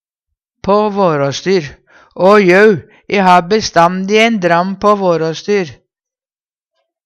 DIALEKTORD PÅ NORMERT NORSK på våråstyr ha i reserve, i bakhand Eksempel på bruk Å jau, e ha bestandi ein dram på vårastyr.